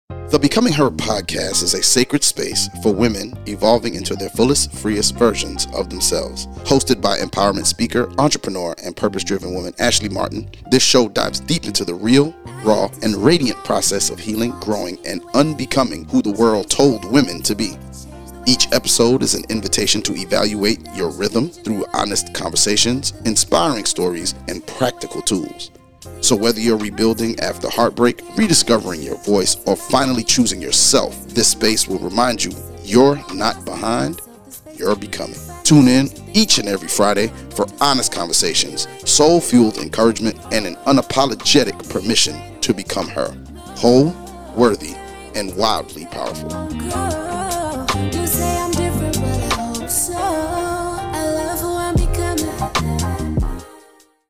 Becoming Her Trailer